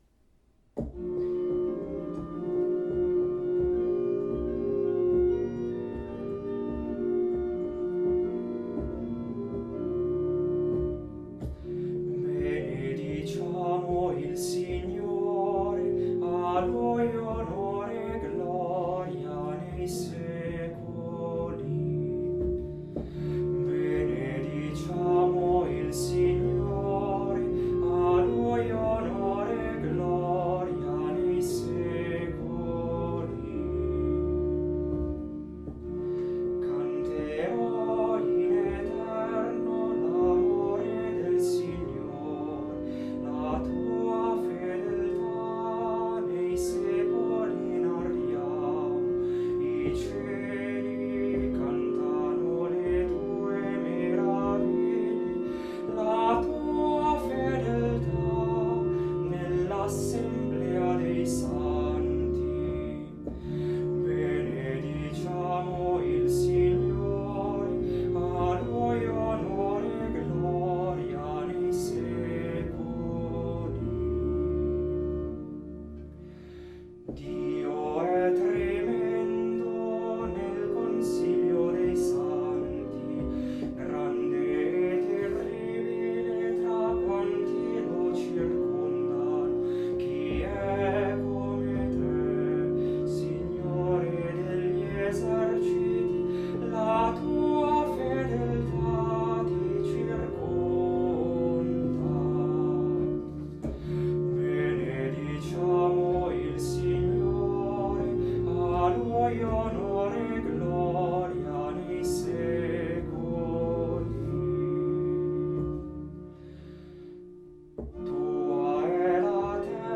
Audio esecuzione a cura degli animatori musicali del Duomo di Milano